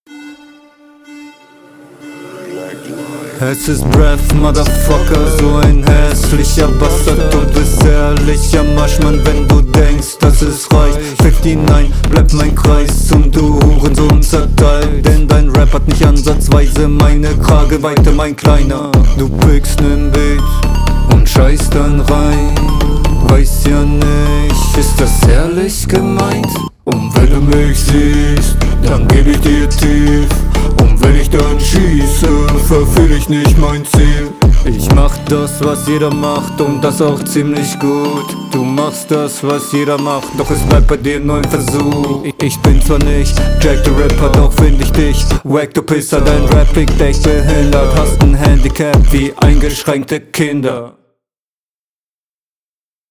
Den Flow finde ich sehr melodisch und gut bis zu dem Flowfehler der dann plötzlich …
Cooler als die HR gerappt und gemixt finde ich und mir gefällts auch echt gut, …